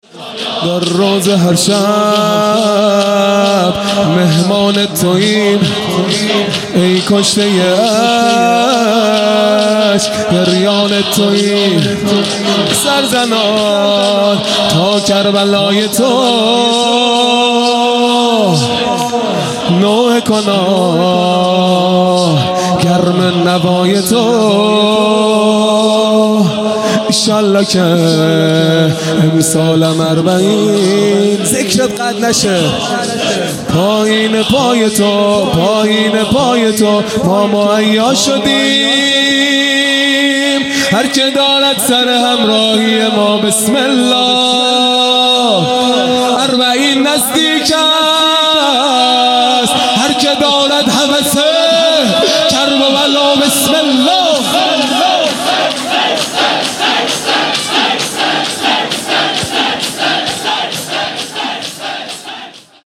خیمه گاه - هیئت بچه های فاطمه (س) - شور | در روضه هر شب
محرم 1441 | شب سوم